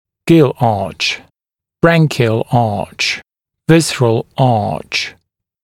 [gɪl ɑːʧ] [‘bræŋkɪəl ɑːʧ] [‘vɪs(ə)r(ə)l ɑːʧ][гил а:ч] [‘брэнкиэл а:ч] [‘вис(э)р(э)л а:ч]жаберная дуга